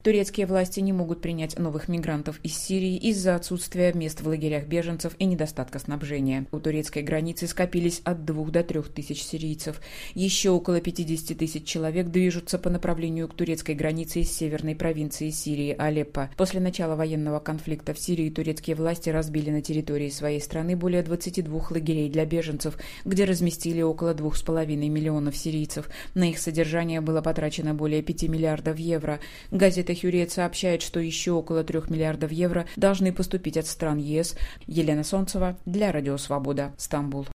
Рассказывает корреспондент